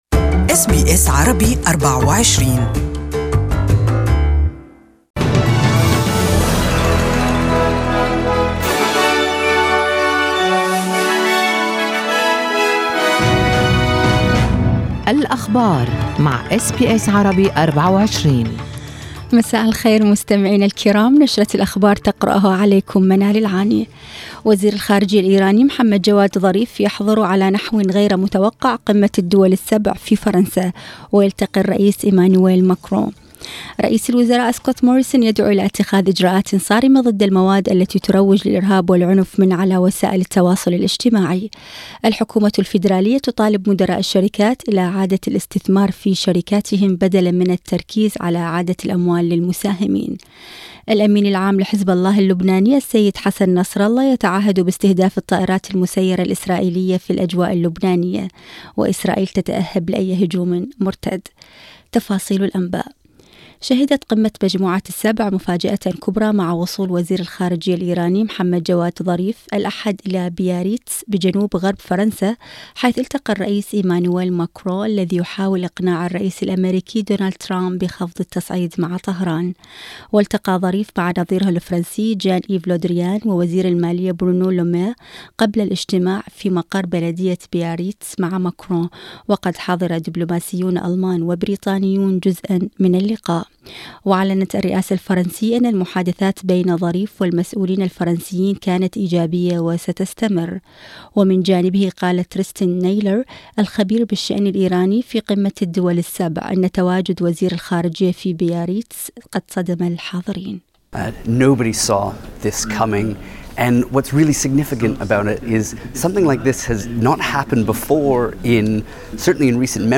يمكن الاستماع لنشرة الأخبار المفصلة باللغة العربية في التقرير الصوتي أعلاه .